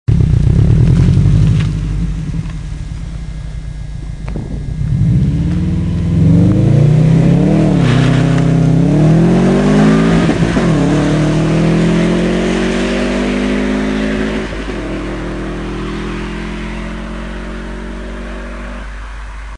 Subaru Impreza (WAV 134Ko)